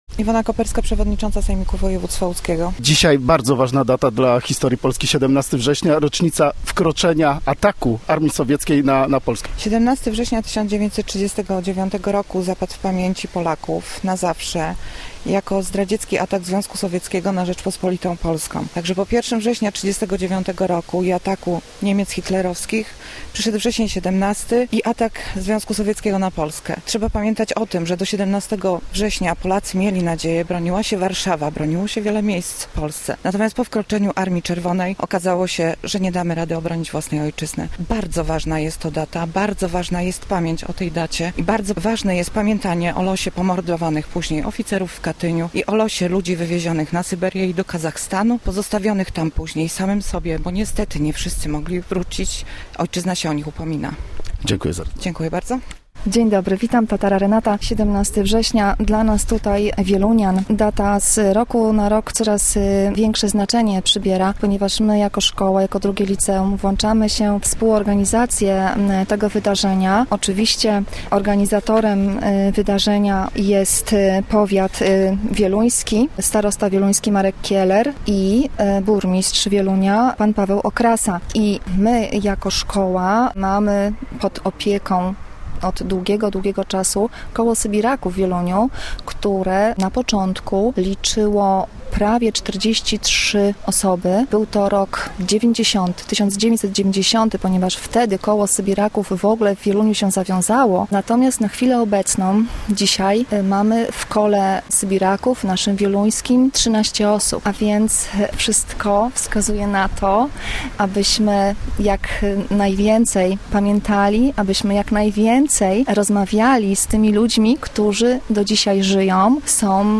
Apelem poległych, złożeniem kwiatów i uroczystą mszą świętą w kościele oo. franciszkanów wieluńska społeczność upamiętniła 81. rocznicę ataku Związku Sowieckiego na Polskę w 1939 roku i Światowy Dzień Sybiraka.